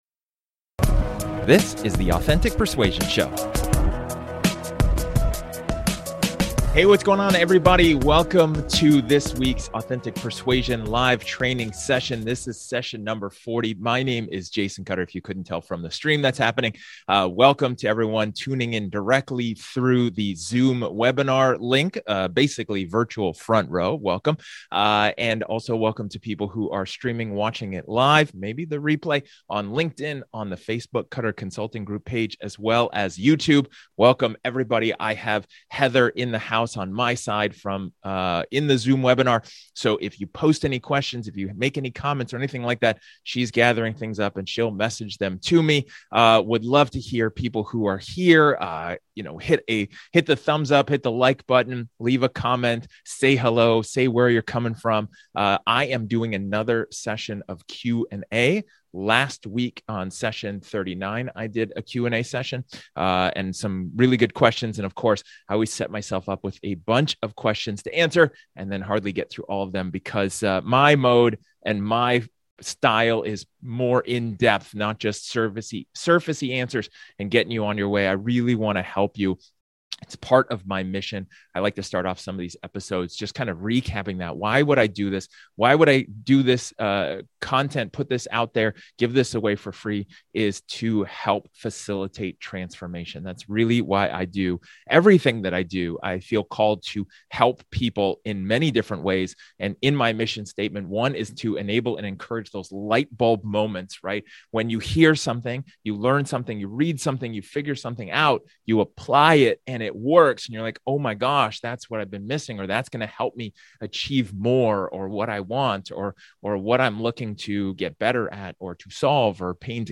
Should I try to sell via email or text? My prospects keep telling me they do not have the budget, what should I do? In this episode, I continue with having Part 8 of my question and answer segment.